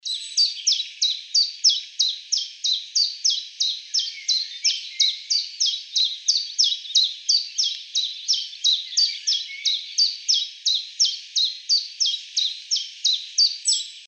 Mosquitero Común (Phylloscopus collybita)
Coombes Valley es una reserva a la que fuí cuando conocí Notingham,había muchos bichos pero difíciles de ver,pero no de grabar
Fase de la vida: Adulto
Localidad o área protegida: Coombes Valley
Condición: Silvestre
Certeza: Vocalización Grabada
Chiffchaff.MP3